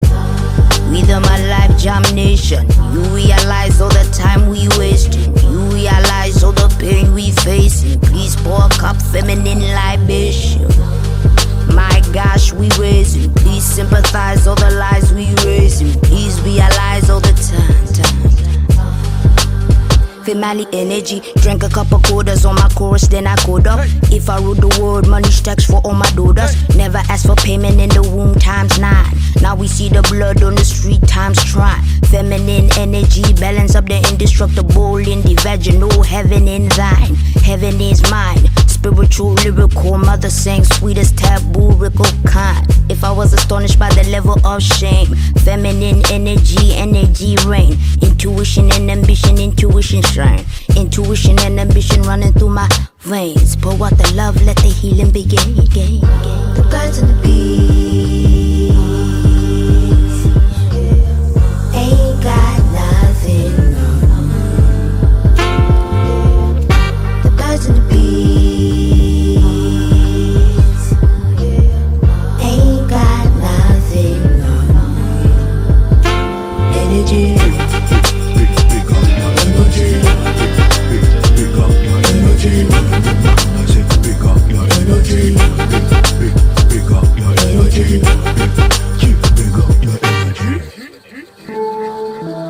• Качество: 320, Stereo
громкие
мощные
Крутой рэпачок